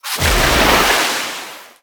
Sfx_creature_penguin_dive_deep_03.ogg